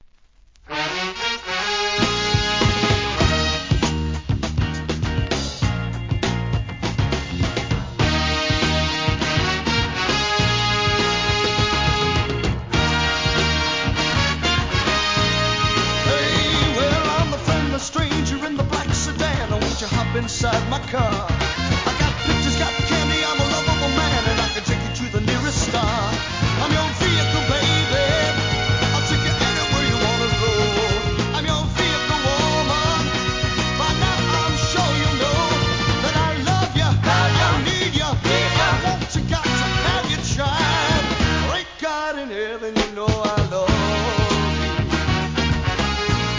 SOUL/FUNK